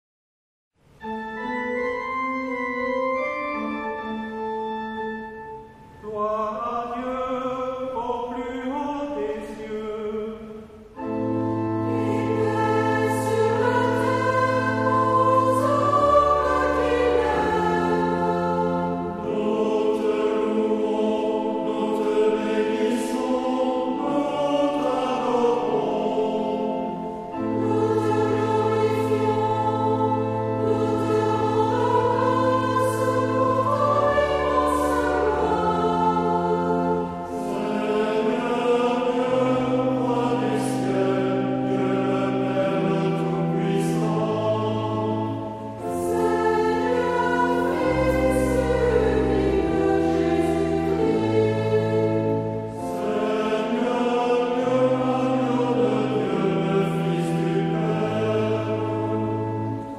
Género/Estilo/Forma: Sagrado ; Himno (sagrado) ; Aclamacion
Carácter de la pieza : alegre
Tipo de formación coral: unisson
Instrumentos: Organo (1)
Tonalidad : la menor